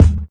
baz_kick.wav